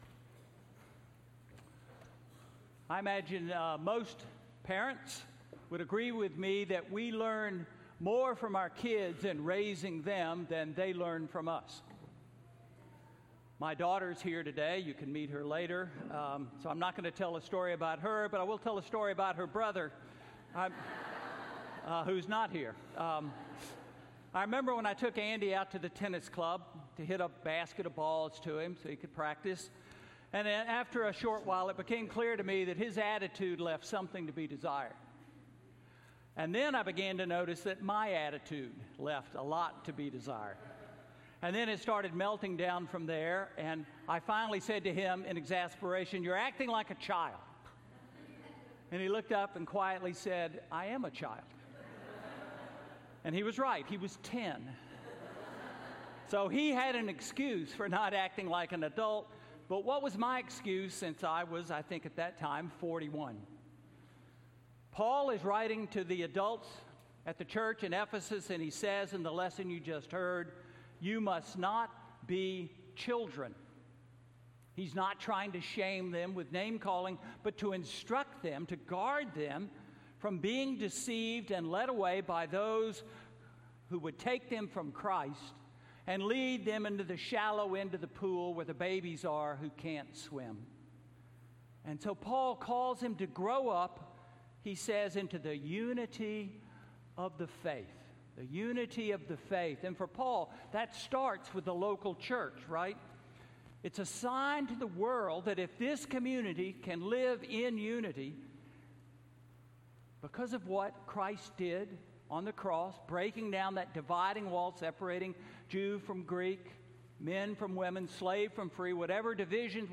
Sermon–New Way of Being Human–August 5, 2018 – All Saints' Episcopal Church